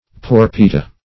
Porpita \Por"pi*ta\, n. [NL., from Gr. ? brooch.] (Zool.)